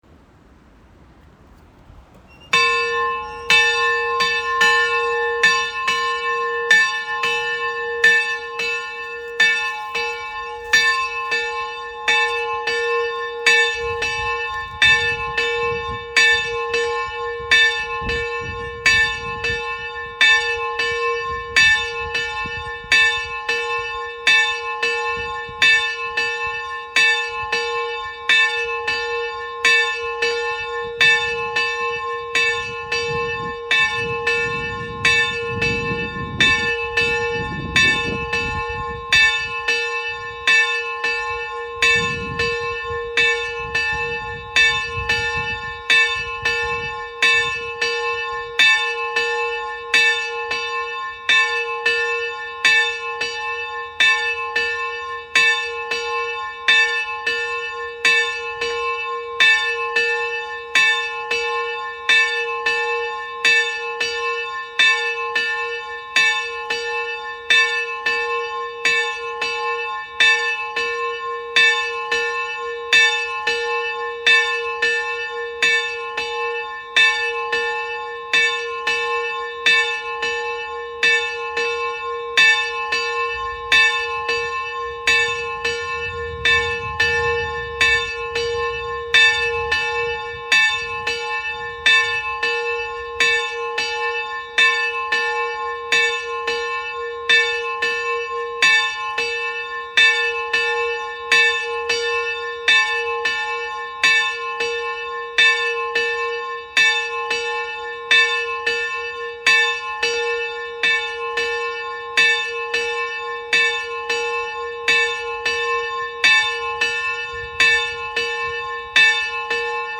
So klingt die Glocke von Hand geläutet (2,0 MiB)